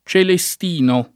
celestino [ © ele S t & no ]